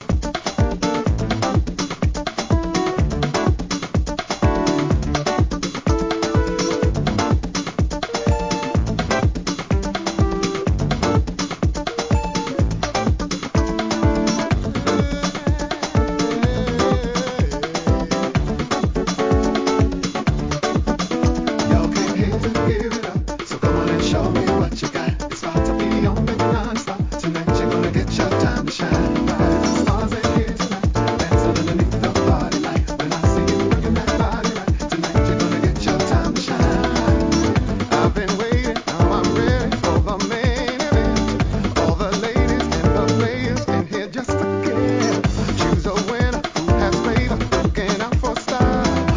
HOUSE REMIX!